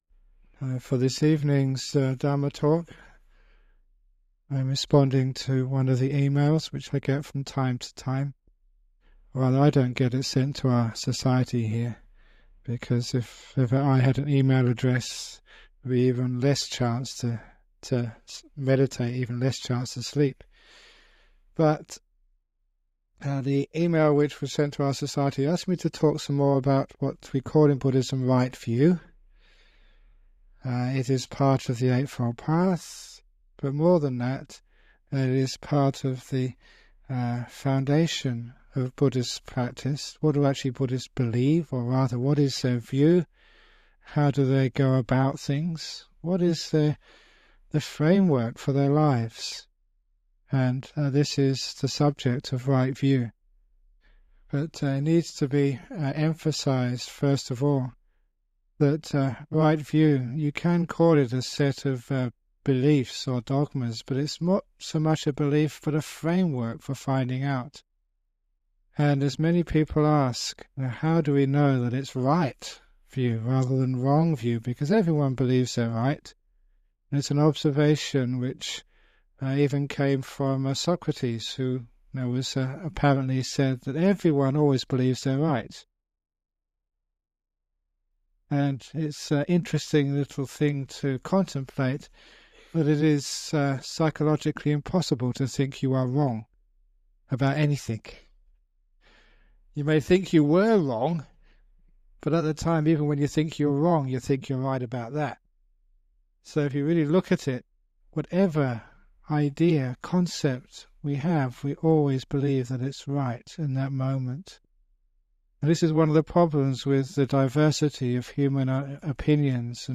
Remastered classic teachings of the greatest meditation master in the modern Western world - Ajahn Brahm!